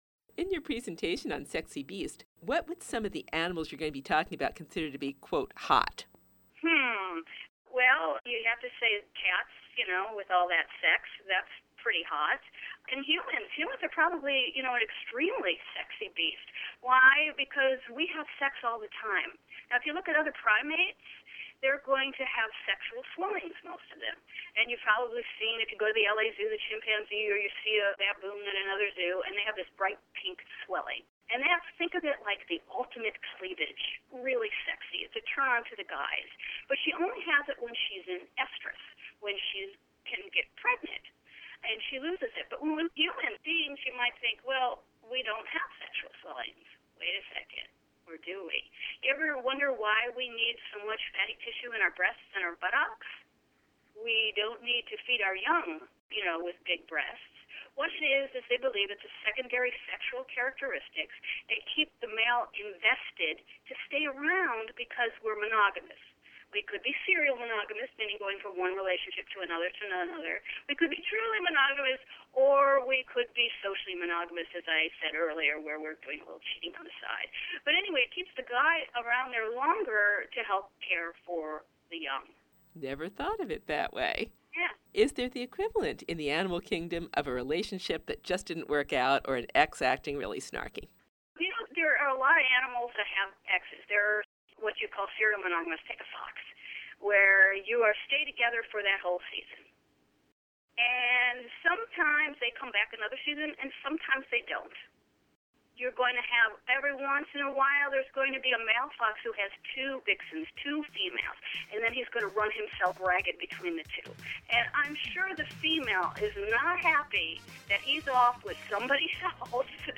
Interview, Part 3